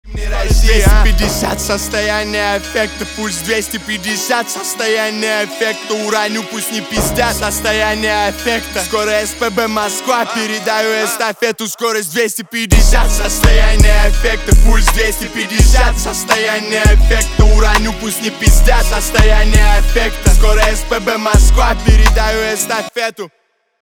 русский рэп
битовые , басы , гитара